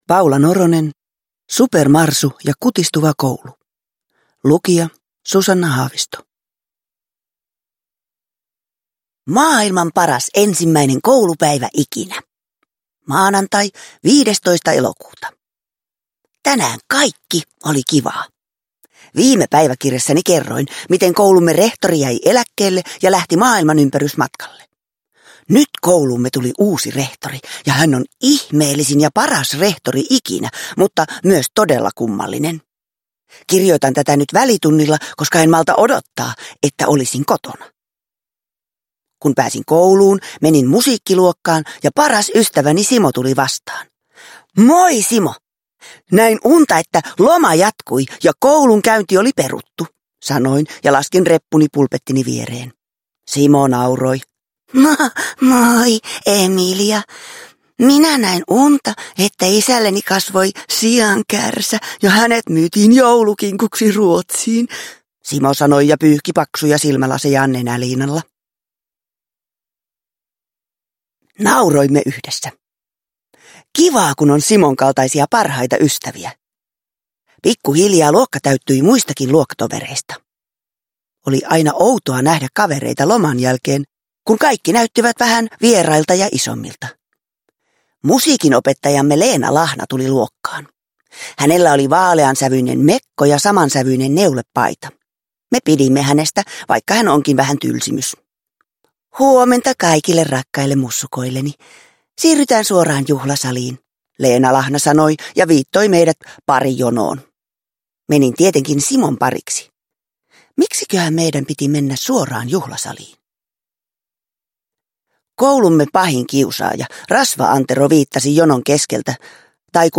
Supermarsu ja kutistuva koulu – Ljudbok – Laddas ner
Uppläsare: Susanna Haavisto